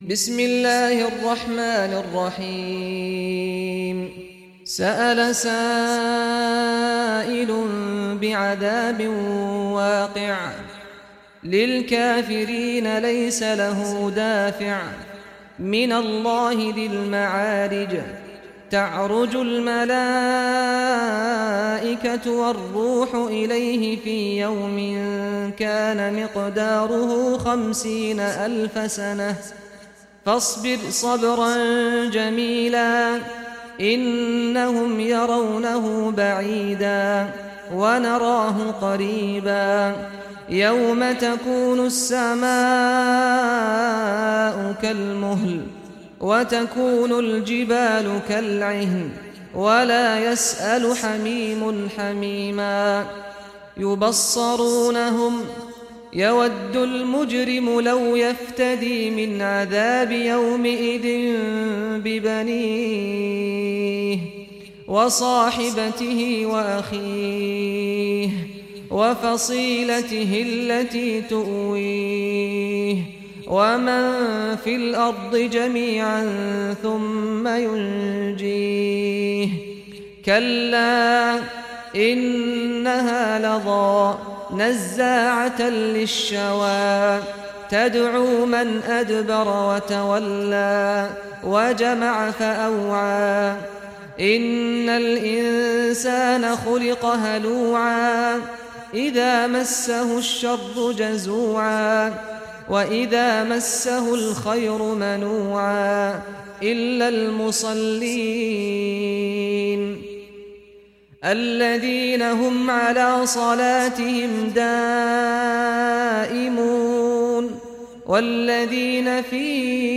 Surah Al Maarij Recitation by Sheikh Saad Ghamdi
Surah Al Maarij, listen or play online mp3 tilawat / recitation in Arabic in the beautiful voice of Sheikh Saad al Ghamdi.